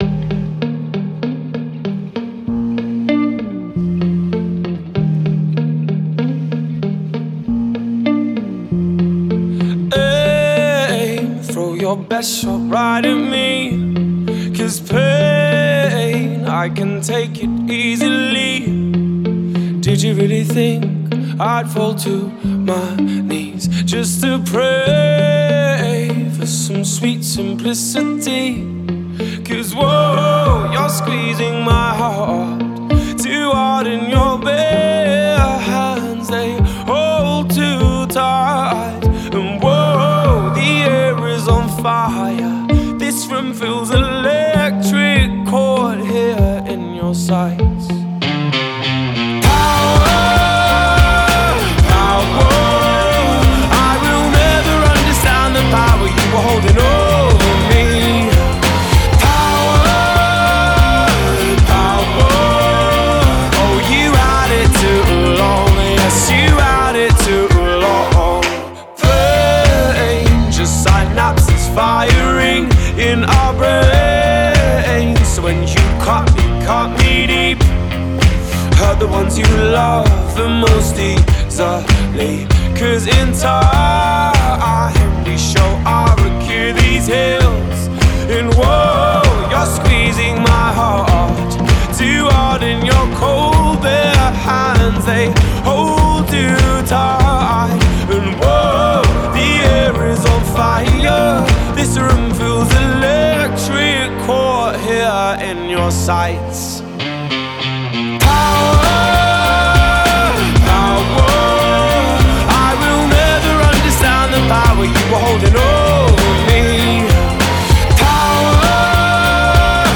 full of catchy hits and clever lyricism